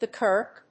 アクセントthe Kírk (of Scótland)